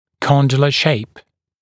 [‘kɔndələ ʃeɪp][‘кондэлэ шэйп]форма мыщелка